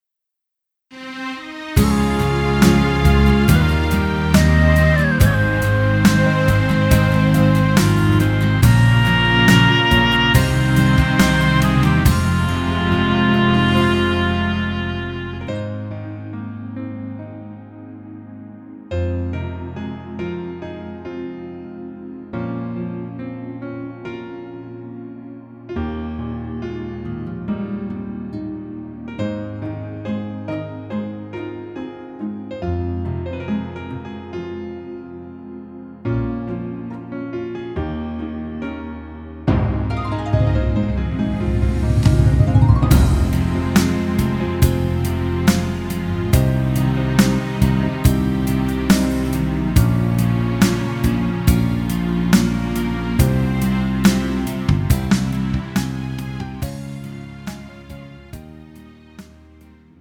음정 남자키 3:16
장르 가요 구분 Pro MR